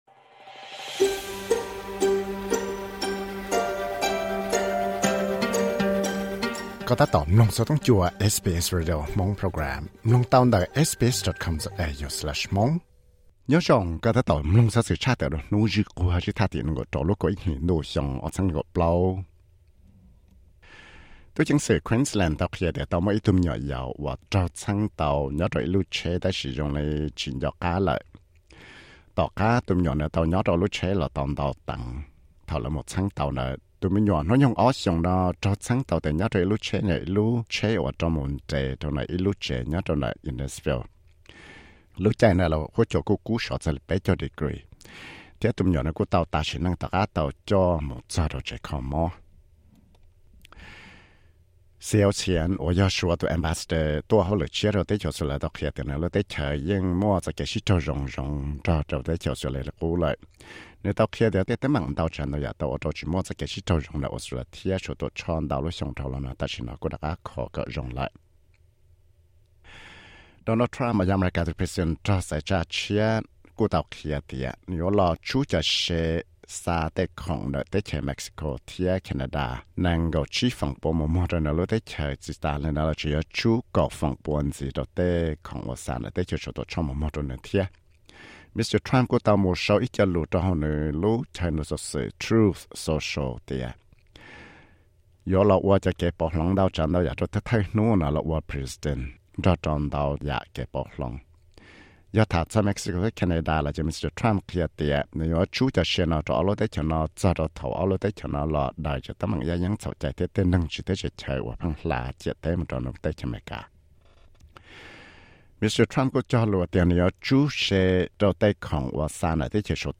Xov xwm luv Credit